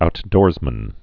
(out-dôrzmən)